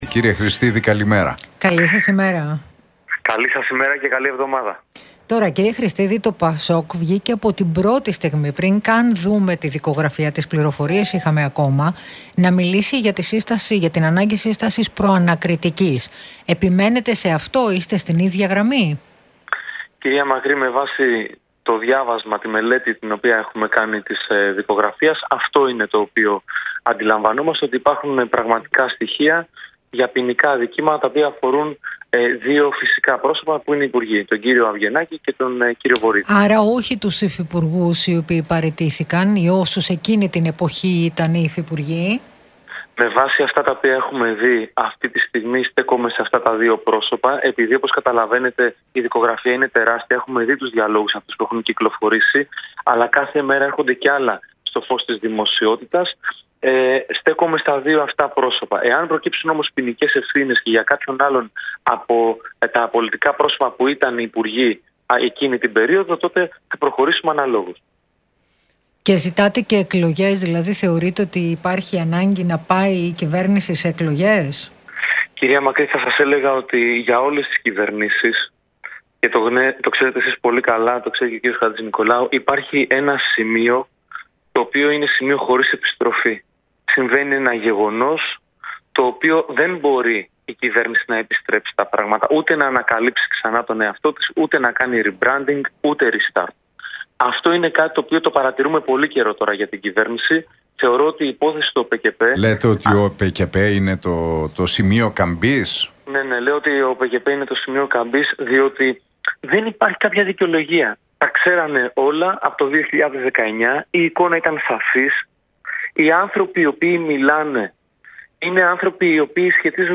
Χρηστίδης στον Realfm 97,8 για ΟΠΕΚΕΠΕ: Είμαστε πιο κοντά σε Προανακριτική - Ο κύριος Μητσοτάκης γνώριζε πάρα πολύ καλά τι συμβαίνει